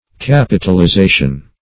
Capitalization \Cap"i*tal*i*za`tion\, n.